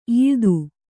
♪ īḷdu